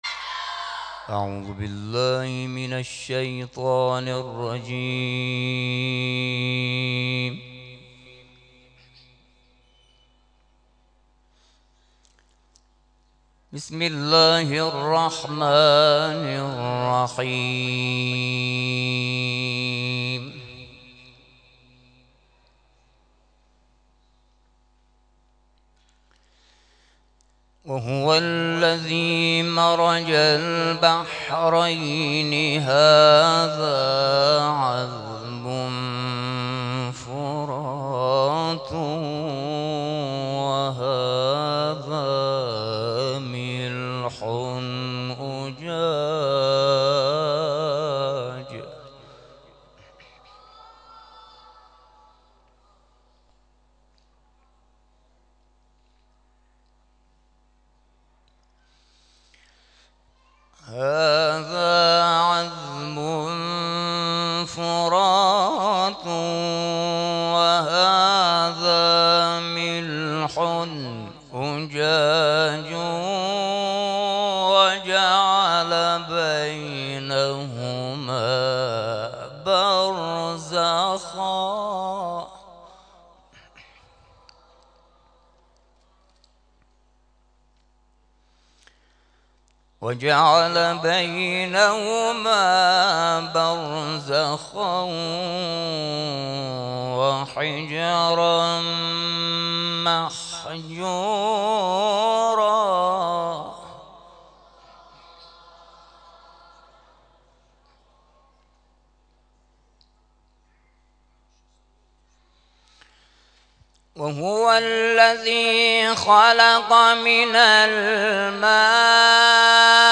تلاوت آیاتی از سوره «فرقان»
تلاوت